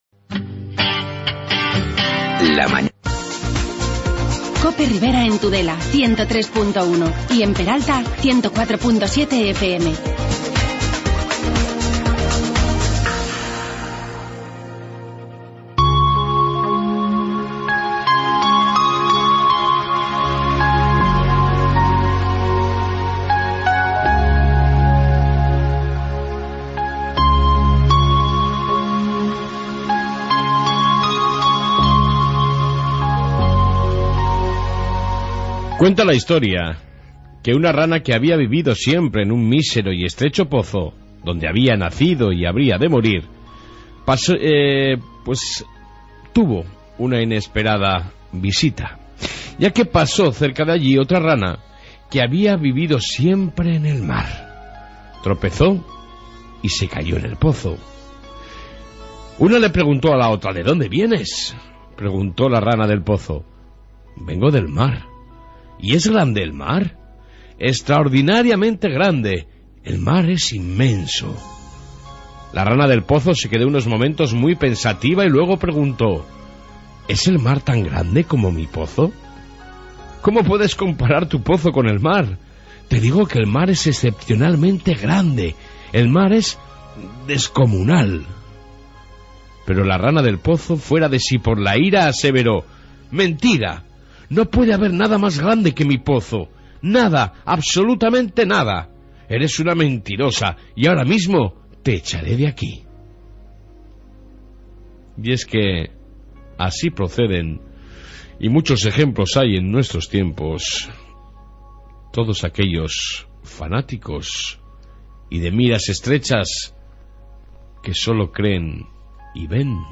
Reflexión Diaria, Información Policia Municipal y entrevista